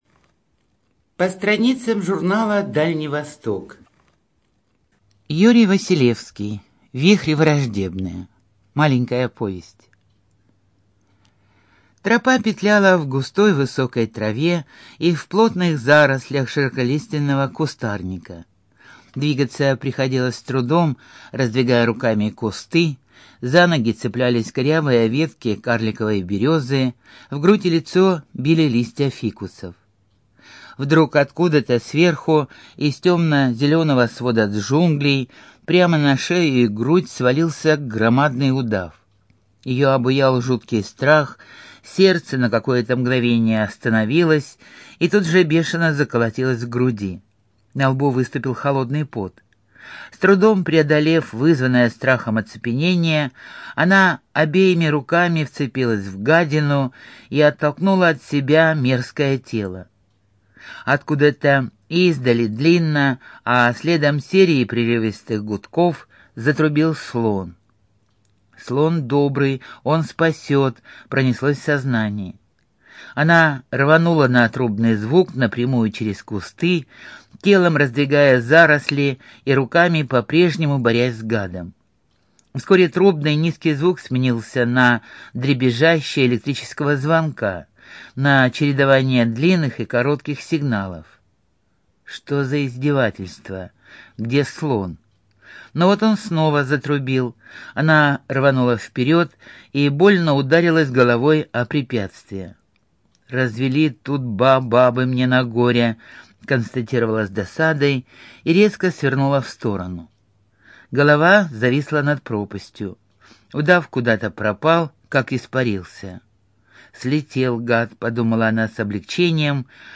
Студия звукозаписиХабаровская краевая библиотека для слепых